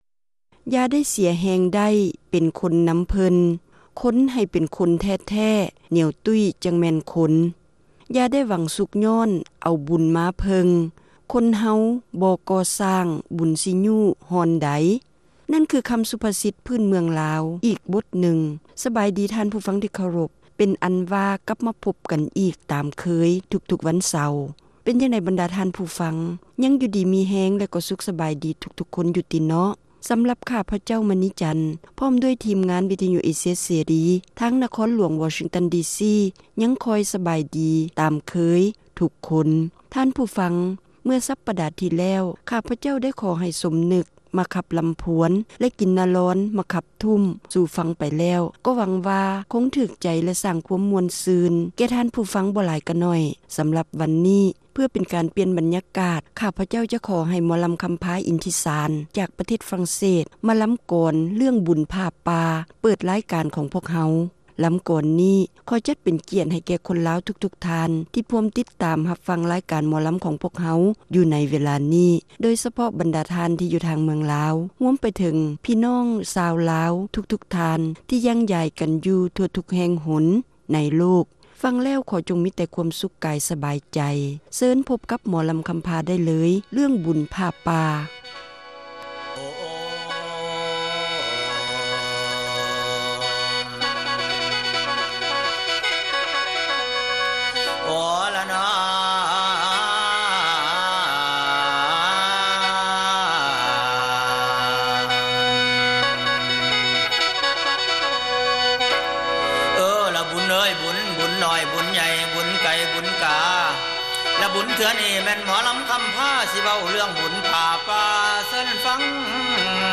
ຣາຍການໜໍລຳ ປະຈຳສັປະດາ ວັນທີ 25 ເດືອນ ມົກະຣາ ປີ 2008